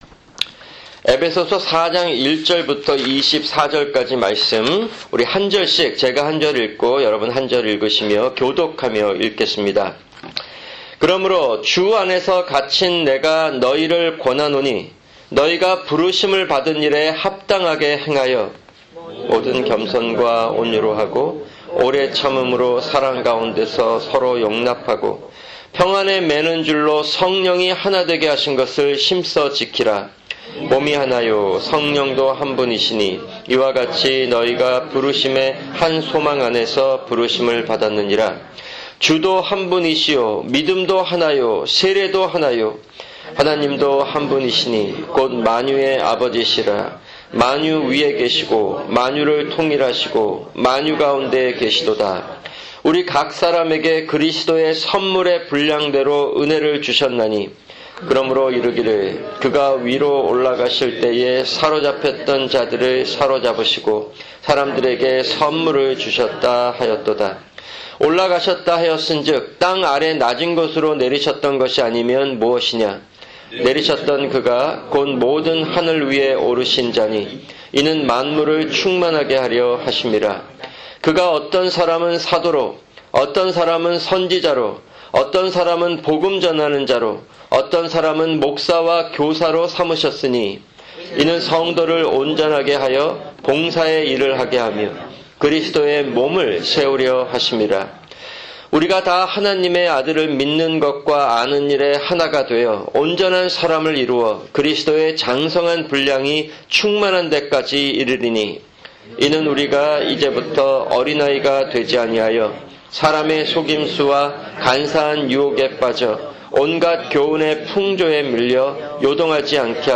[금요 성경공부] 에베소서4:17-24(1)